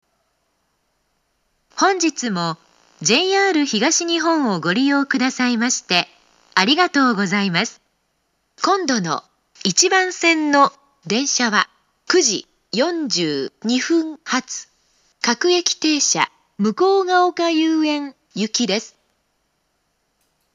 １番線到着予告放送